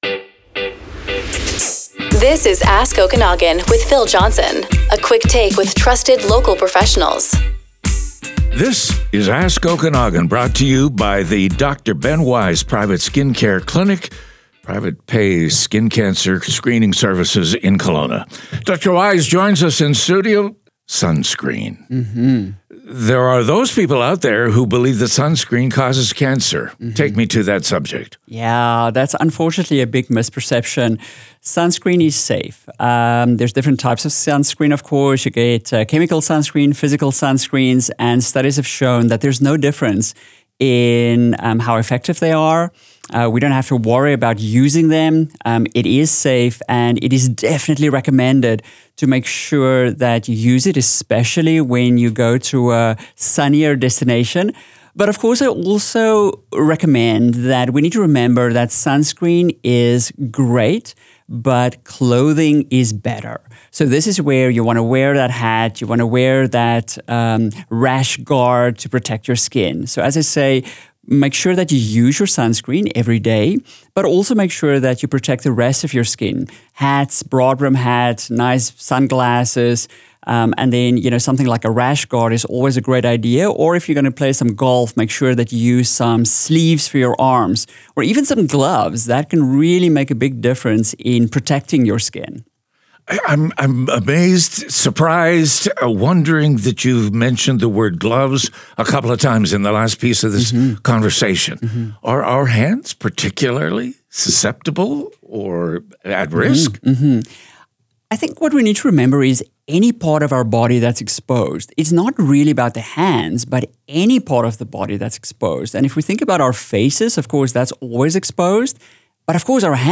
Interviews
This media recording reflects a public interview and is shared for educational purposes only.